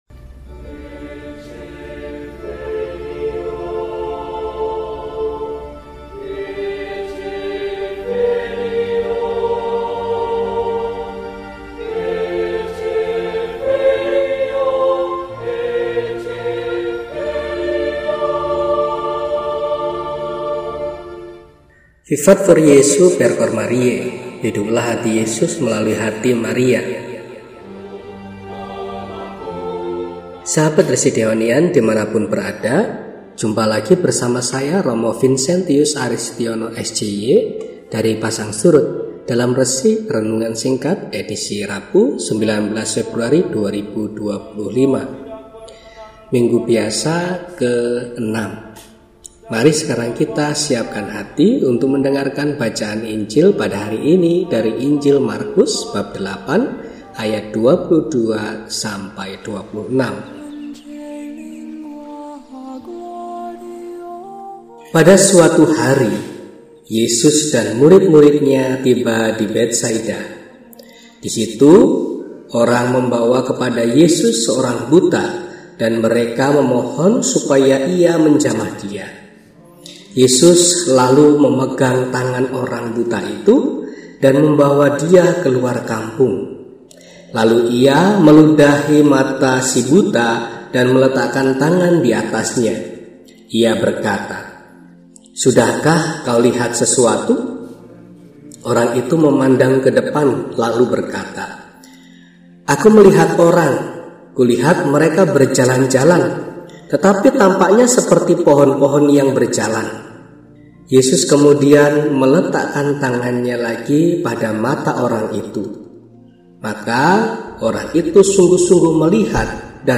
Rabu, 19 Februari 2025 – Hari Biasa Pekan VI – RESI (Renungan Singkat) DEHONIAN